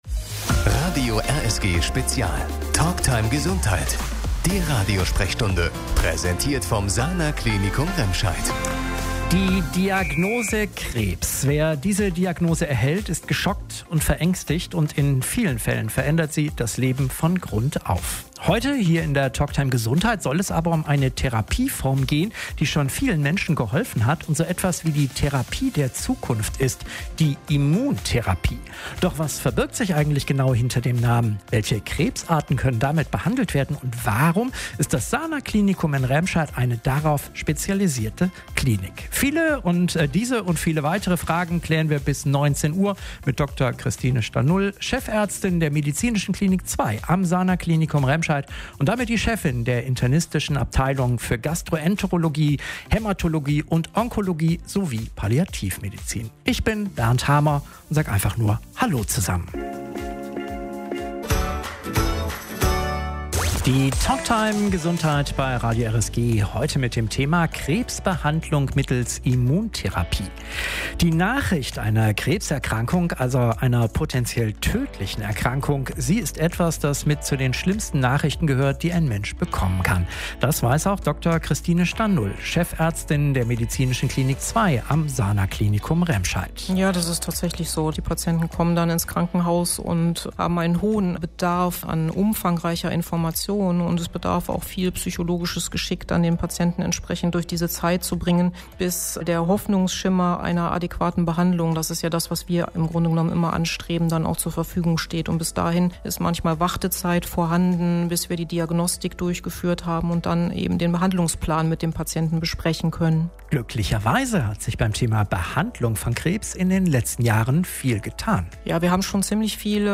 Die Sendung steht jetzt hier zum Nachhören bereit.